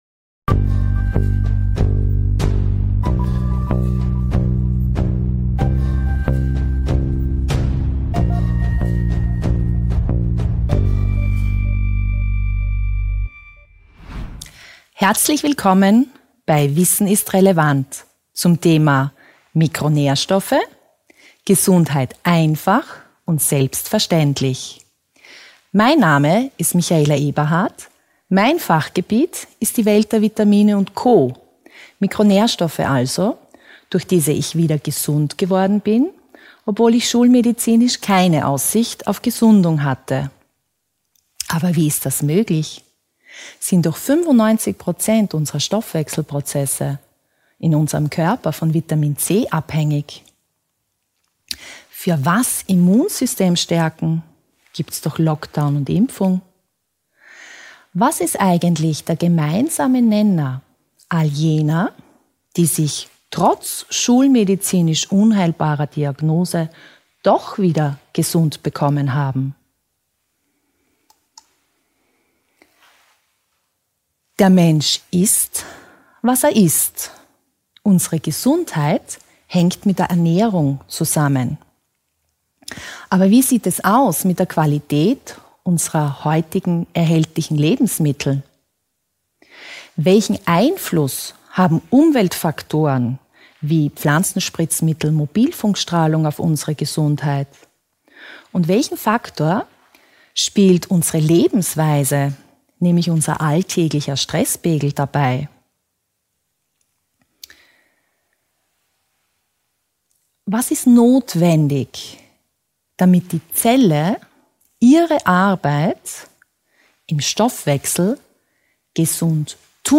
Wissen ist relevant – Gesundheit aber auch. Im folgenden Vortrag